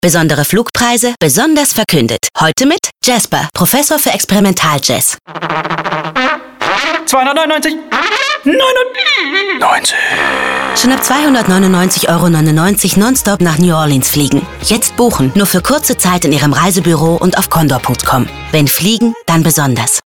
Radio spots: